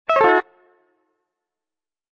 descargar sonido mp3 guitarra 19